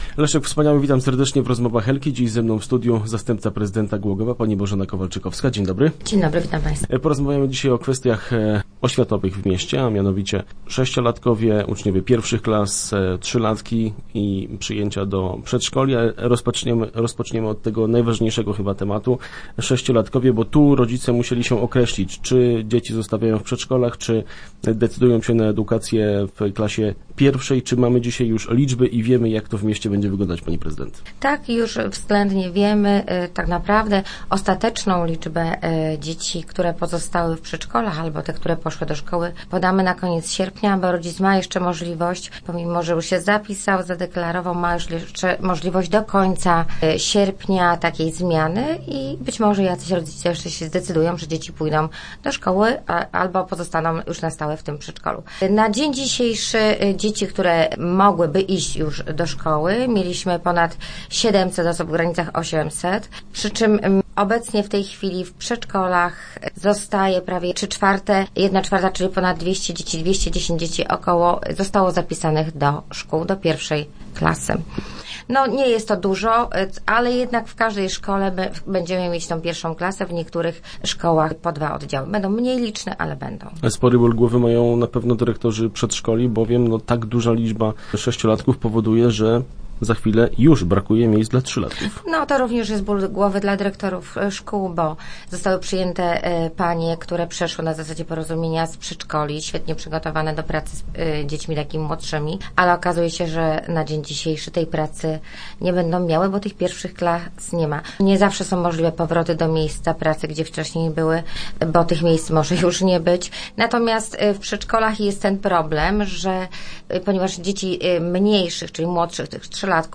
W środę w radiowym studiu gościliśmy zastępcę prezydenta miasta Bożenę Kowalczykowską. Tematem Rozmów Elki była rekrutacja do szkół i przedszkoli. Poruszyliśmy też kwestię nowych miejsc w żłobku.